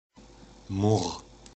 The Murr (German: [mʊʁ]